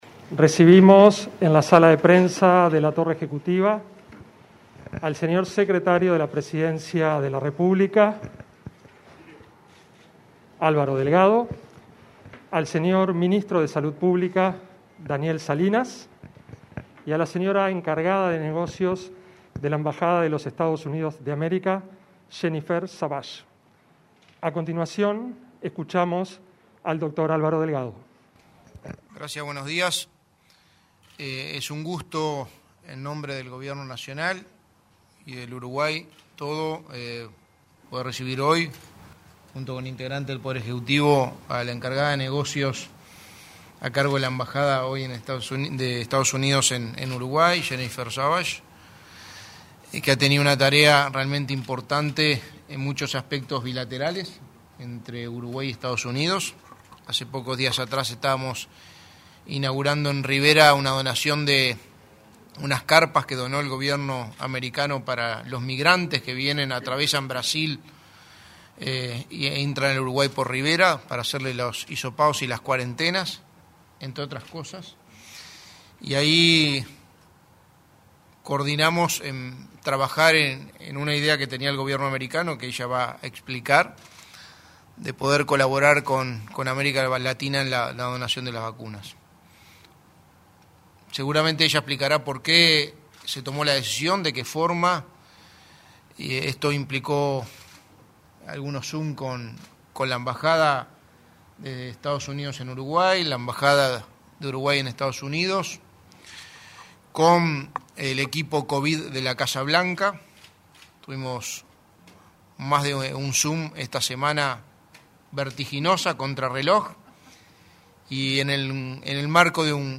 Conferencia de prensa donación de vacunas Pfizer de Estados Unidos a Uruguay
Conferencia de prensa donación de vacunas Pfizer de Estados Unidos a Uruguay 25/06/2021 Compartir Facebook X Copiar enlace WhatsApp LinkedIn Este viernes 25 se realizó una conferencia de prensa en la sala de prensa de Torre Ejecutiva con la presencia del secretario de Presidencia, Álvaro Delgado acompañado por el ministro de Salud Pública, Daniel Salinas y la encargada de negocios de Estados Unidos. En la ocasión. los jerarcas anunciaron que el país del norte donará 500.000 dosis de la vacuna Pfizer a Uruguay.